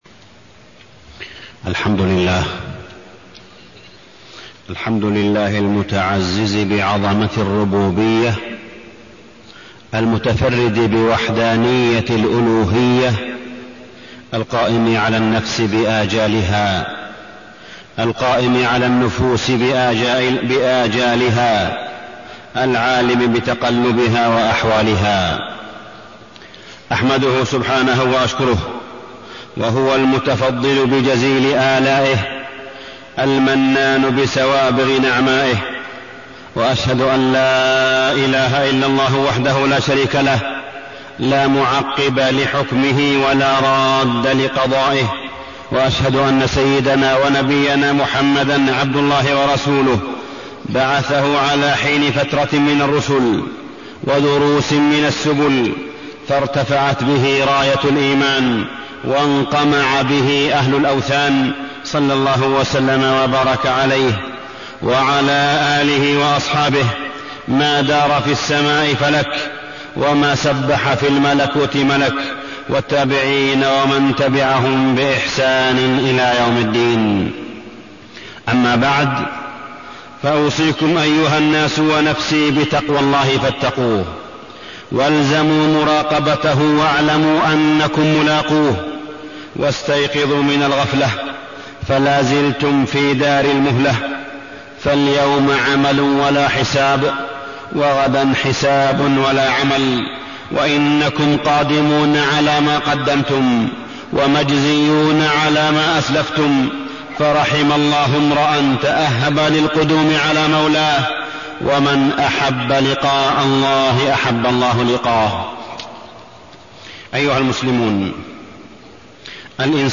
تاريخ النشر ٢٤ شعبان ١٤٢٢ هـ المكان: المسجد الحرام الشيخ: معالي الشيخ أ.د. صالح بن عبدالله بن حميد معالي الشيخ أ.د. صالح بن عبدالله بن حميد الإمام المجدد محمد بن عبدالوهاب